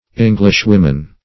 englishwomen.mp3